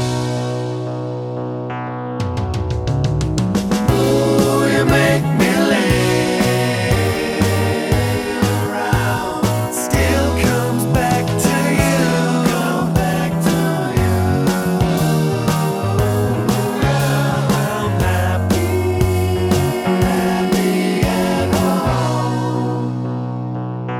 Down One Semitone Rock 2:51 Buy £1.50